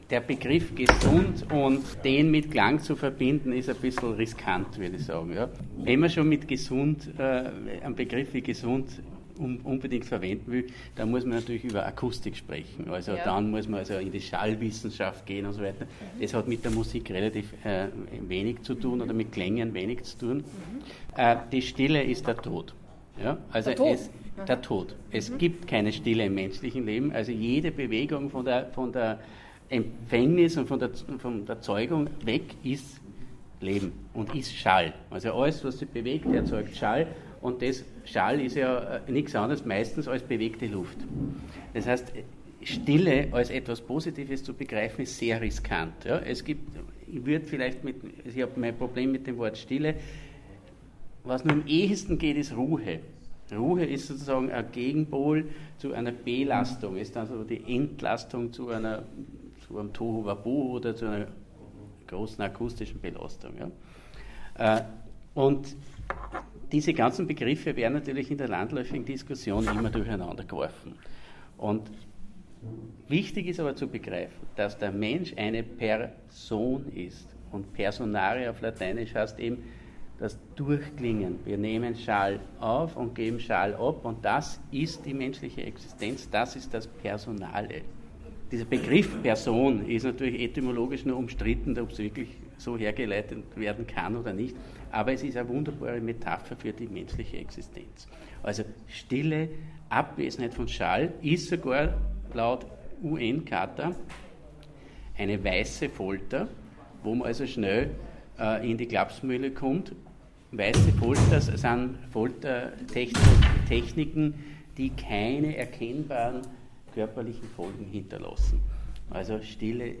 Die lebendige Diskussion geben auch die Hörbeispiele wider.